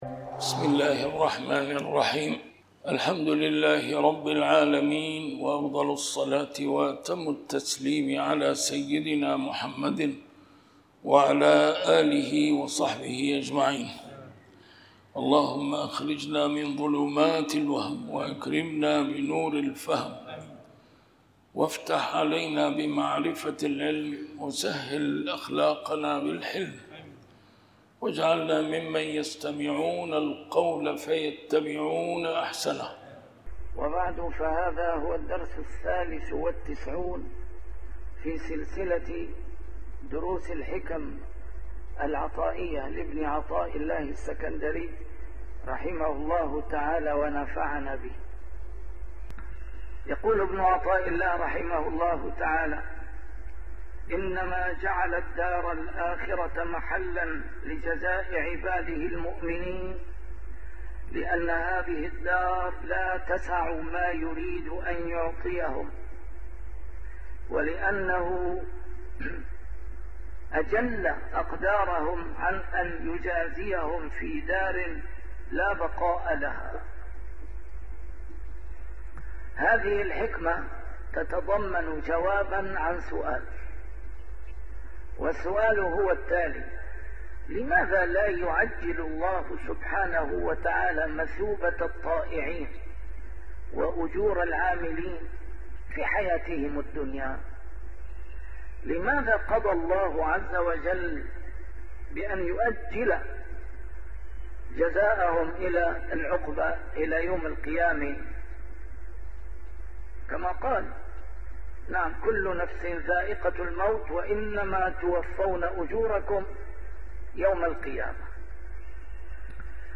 A MARTYR SCHOLAR: IMAM MUHAMMAD SAEED RAMADAN AL-BOUTI - الدروس العلمية - شرح الحكم العطائية - الدرس رقم 93 شرح الحكمة 71